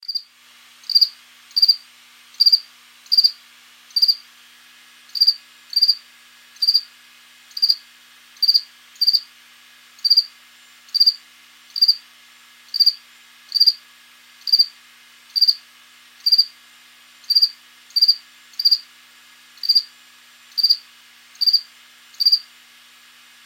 Cricket
cricket.ogg